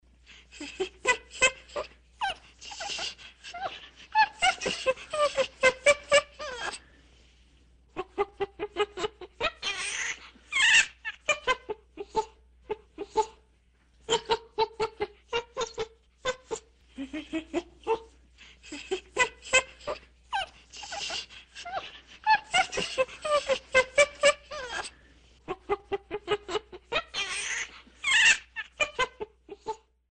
На этой странице собраны разнообразные звуки шимпанзе — от радостных возгласов до предупреждающих криков.
Звуки шимпанзе